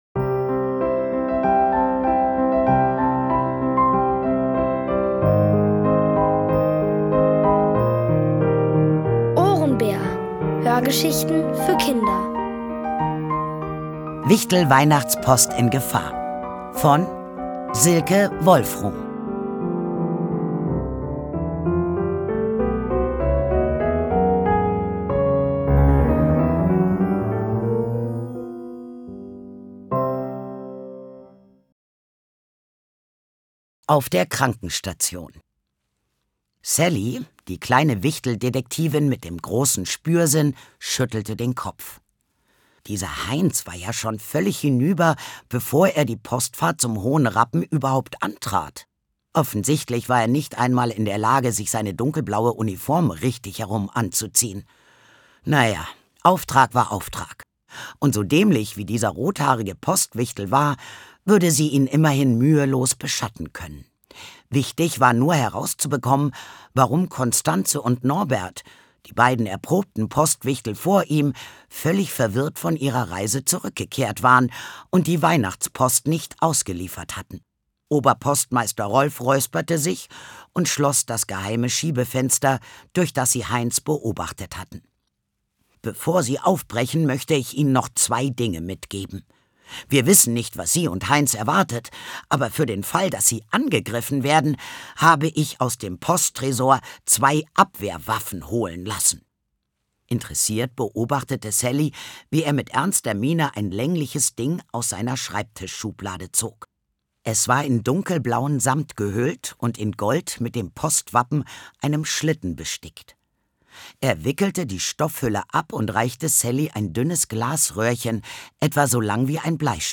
Von Autoren extra für die Reihe geschrieben und von bekannten Schauspielern gelesen.
Es liest: Sandra Schwittau.